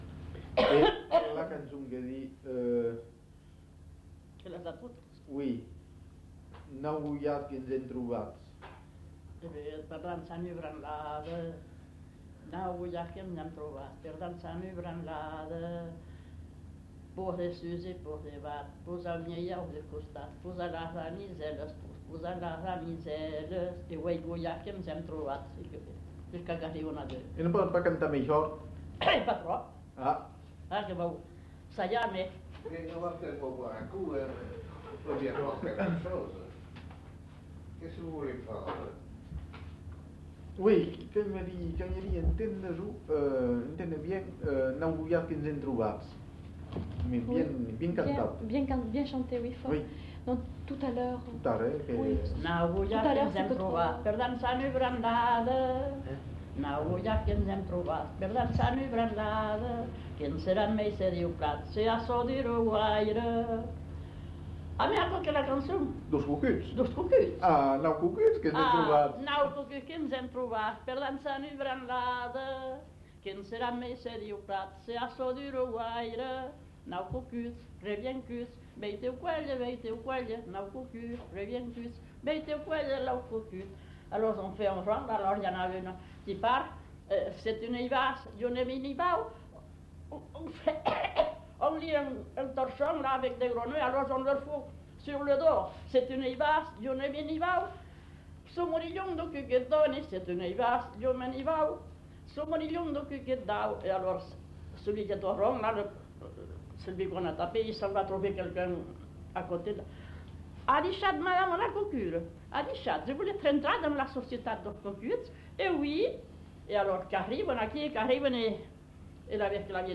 Aire culturelle : Marsan
Genre : chant
Effectif : 1
Type de voix : voix de femme
Production du son : chanté
Notes consultables : L'interprète n'est pas identifiée.